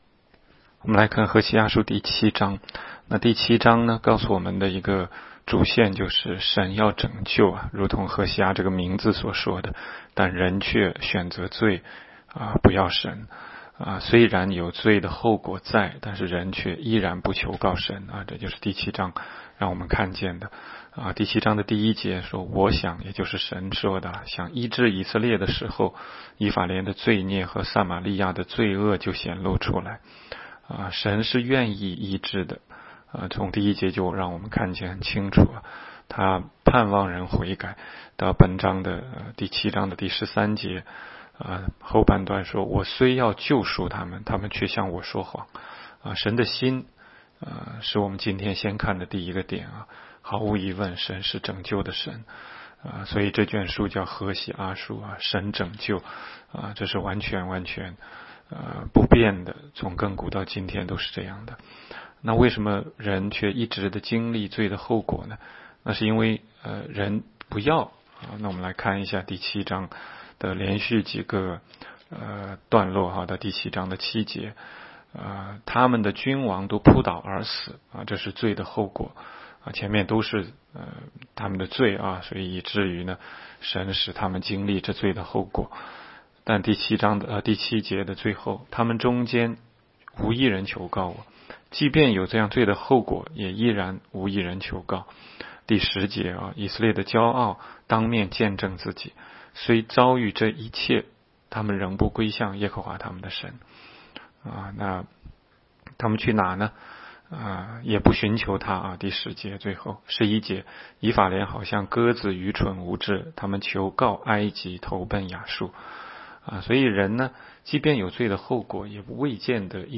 16街讲道录音 - 每日读经 -《何西阿书》7章